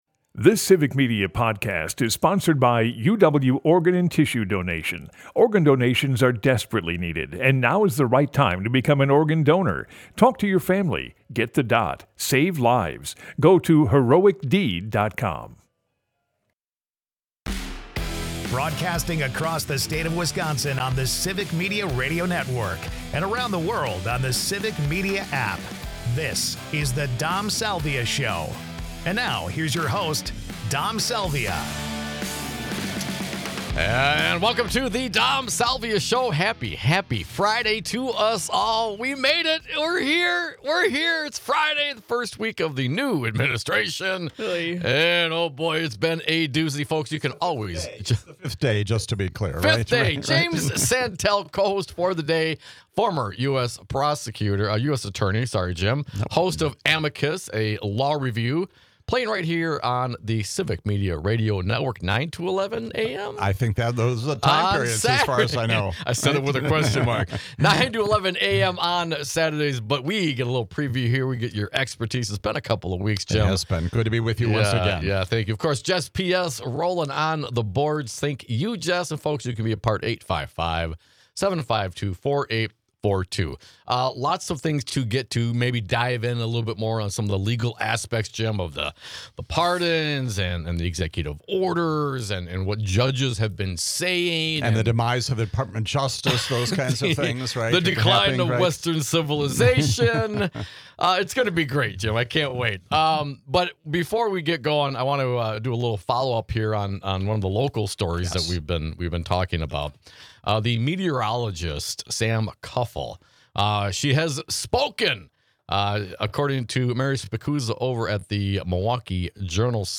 news (some), humor (more), and great conversation (always)!